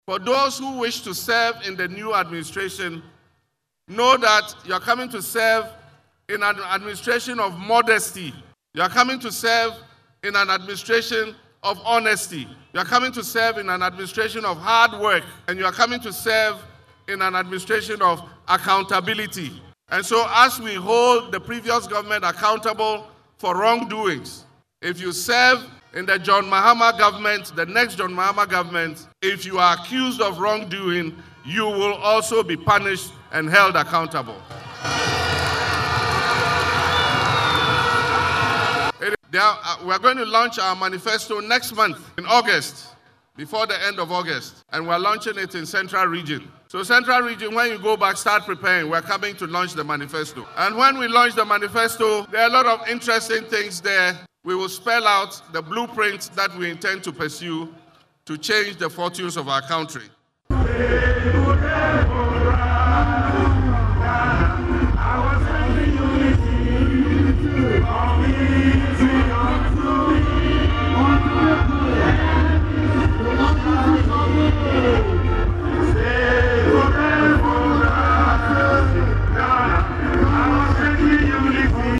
Speaking at the grand launch of the party’s campaign in Tamale, Northern Region, Mr. Mahama emphasised that those who wish to serve in the new administration must be committed to modesty, honesty, hard work, and accountability.
Listen to full audio of John Dramani Mahama: